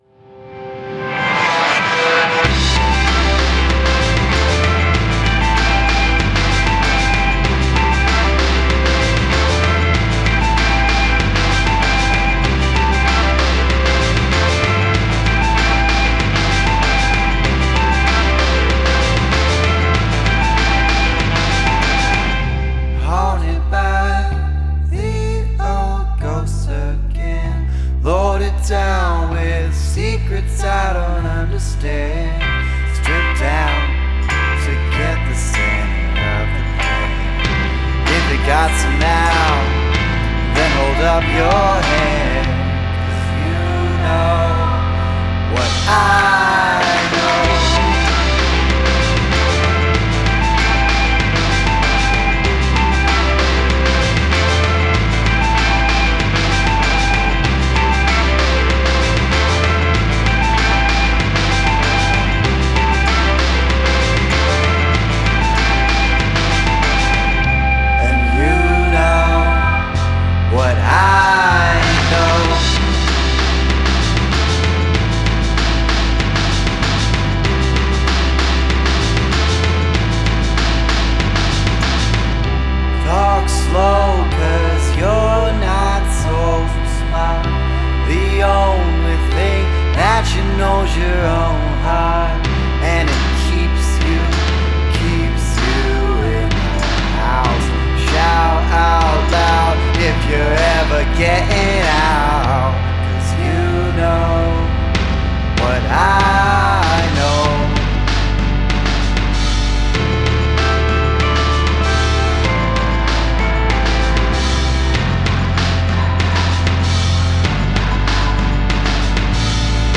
Indie Indie rock Indie pop Art pop